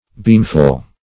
Beamful \Beam"ful\